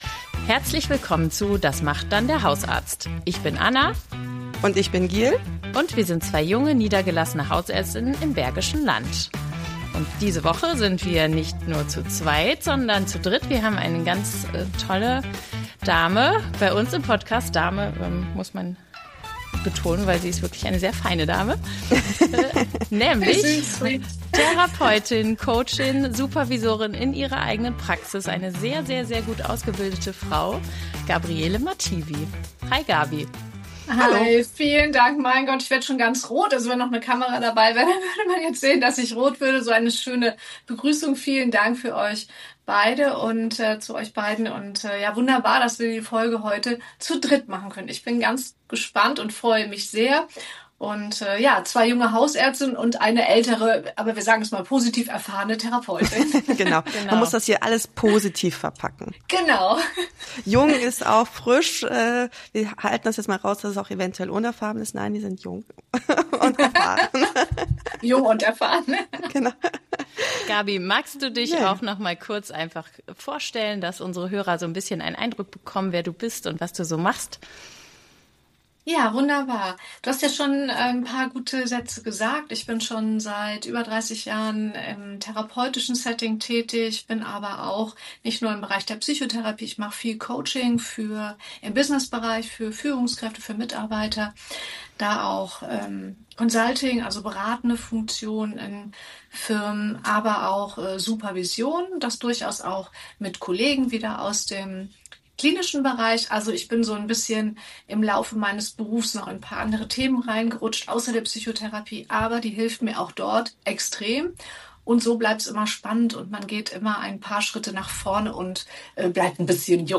Gespräch mit Psychotherapeutin